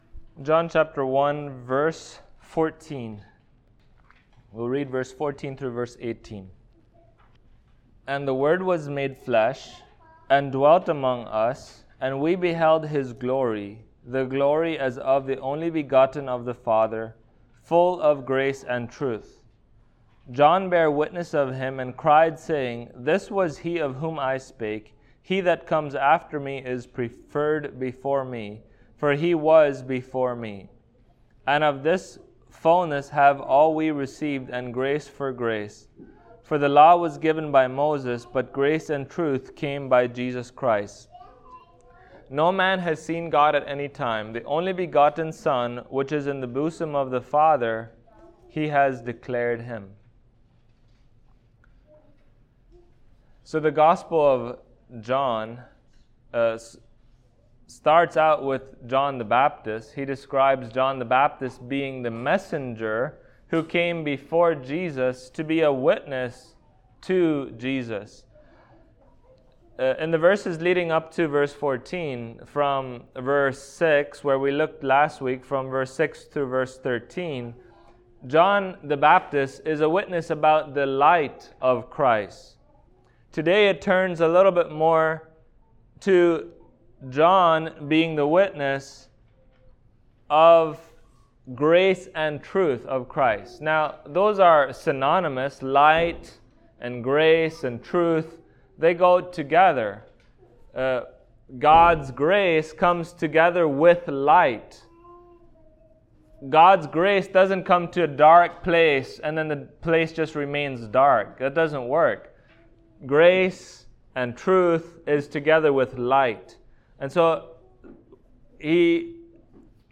John Passage: John 1:14-18 Service Type: Sunday Morning Topics